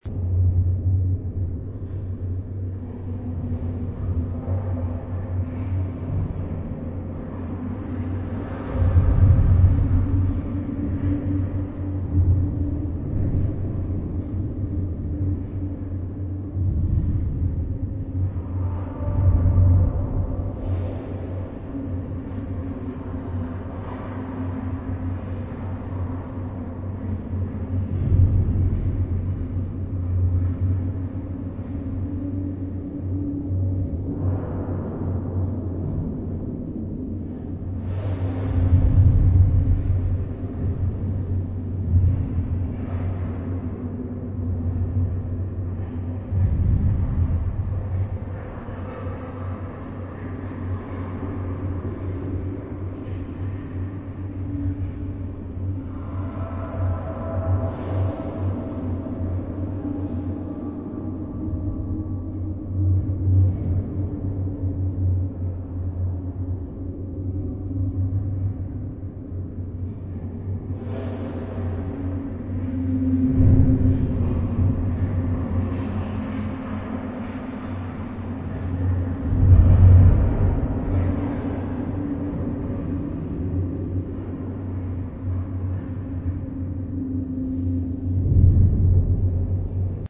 zone_field_asteroid_ice.wav